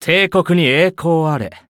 文件 文件历史 文件用途 全域文件用途 Ja_Bhan_amb_01.ogg （Ogg Vorbis声音文件，长度1.7秒，111 kbps，文件大小：23 KB） 源地址:游戏语音 文件历史 点击某个日期/时间查看对应时刻的文件。 日期/时间 缩略图 大小 用户 备注 当前 2018年5月25日 (五) 02:58 1.7秒 （23 KB） 地下城与勇士  （ 留言 | 贡献 ） 分类:巴恩·巴休特 分类:地下城与勇士 源地址:游戏语音 您不可以覆盖此文件。